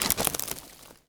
wood_tree_branch_move_08.wav